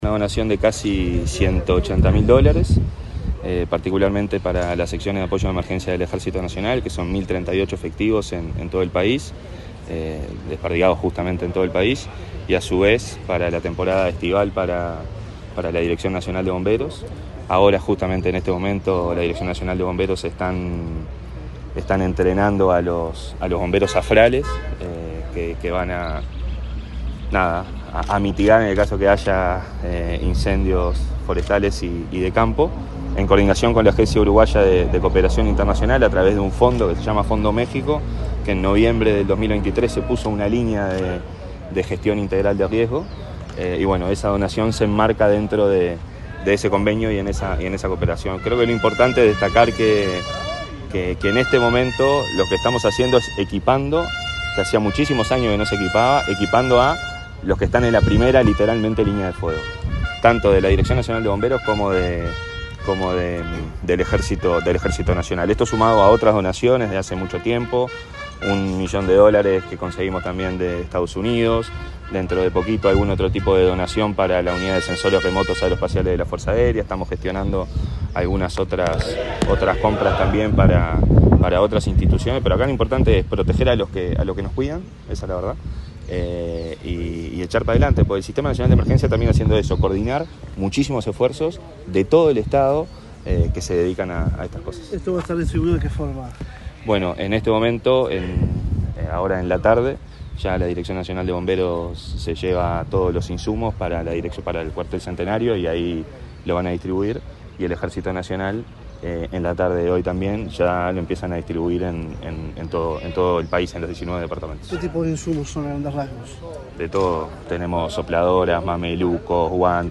Declaraciones del director del Sinae, Santiago Caramés
Luego, Caramés dialogó con la prensa acerca del alcance de esta acción.